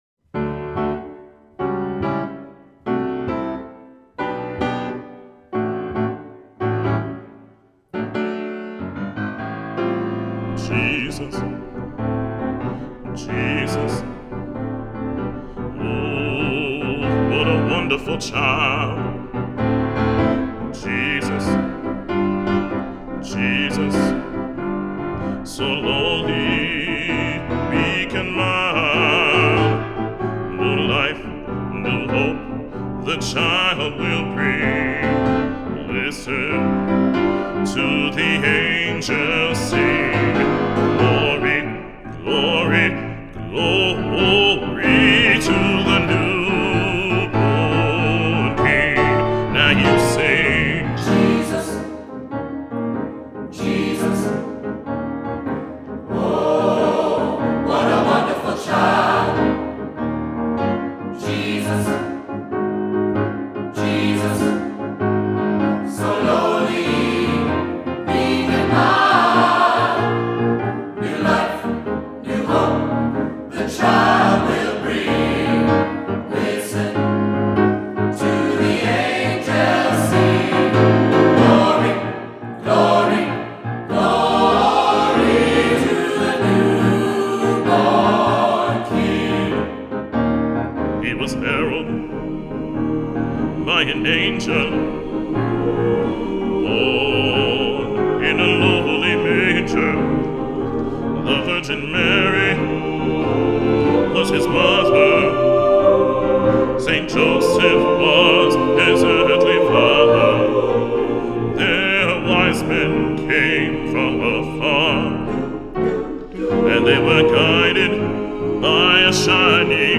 Voicing: SATB,Soloist or Soloists